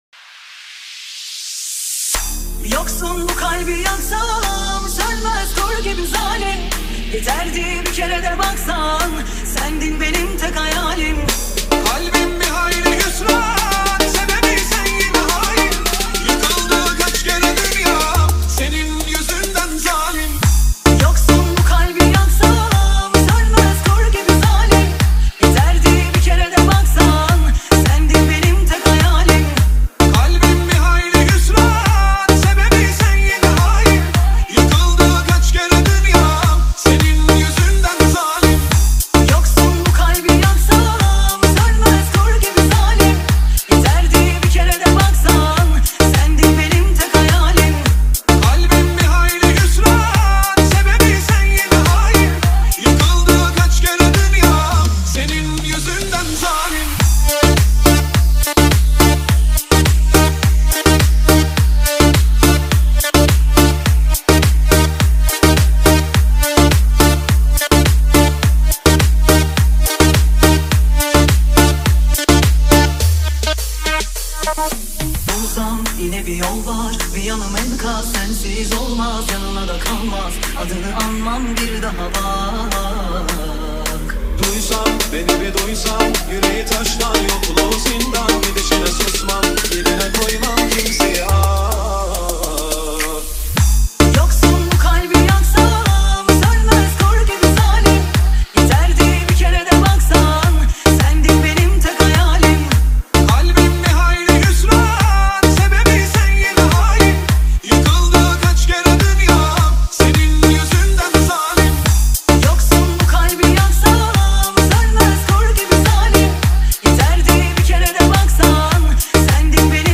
ریمیکس ترکی